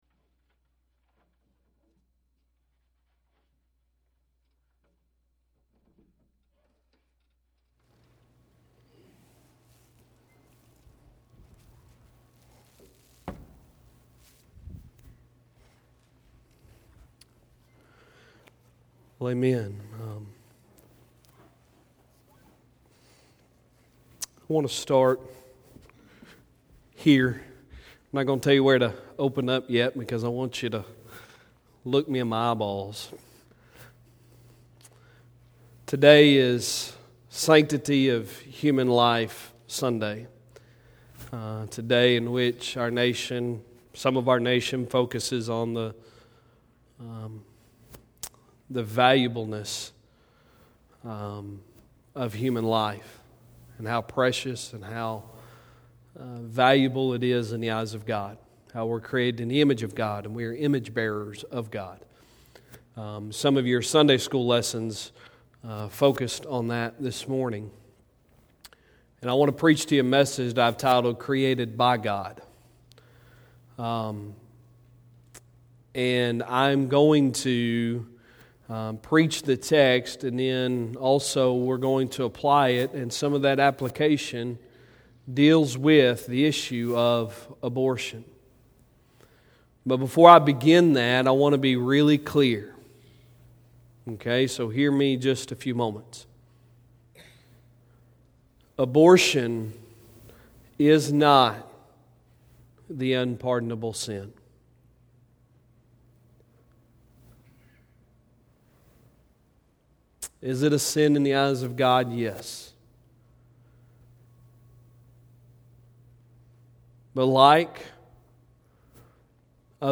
Sunday Sermon January 19, 2020